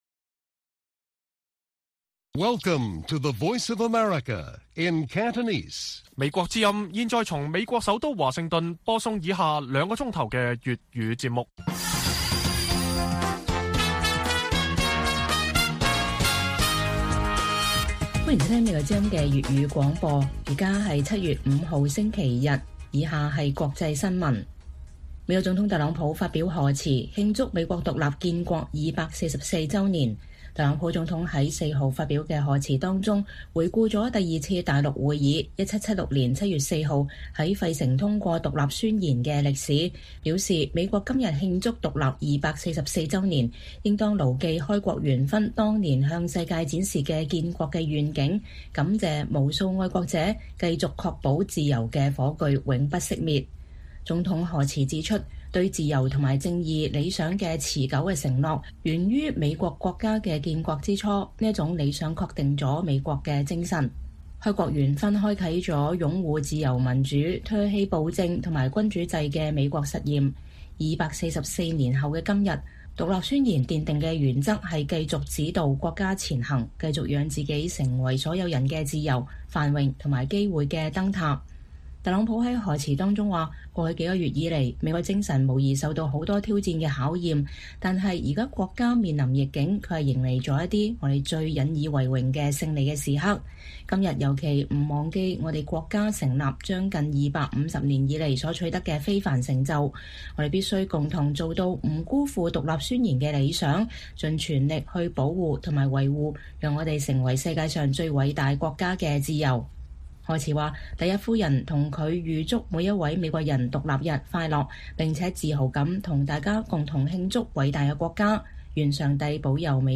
粵語新聞 晚上9-10點